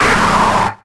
Grito de Snubbull.ogg
Grito_de_Snubbull.ogg.mp3